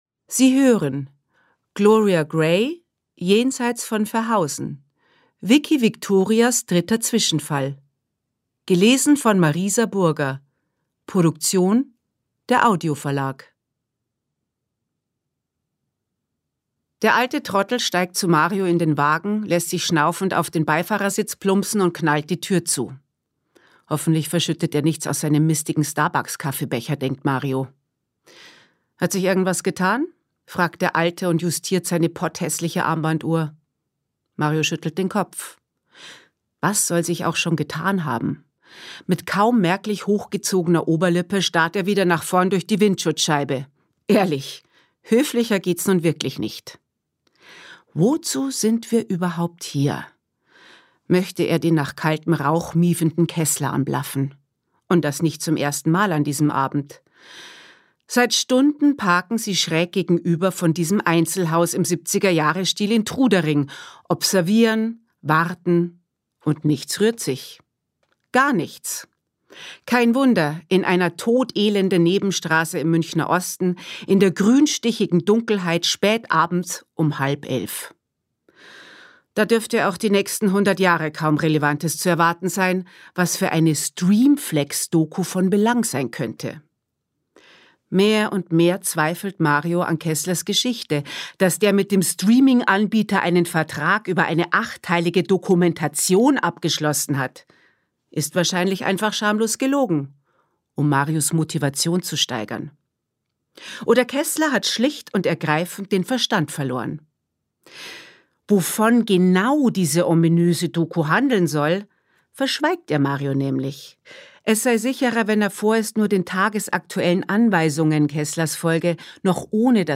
Marisa Burger (Sprecher)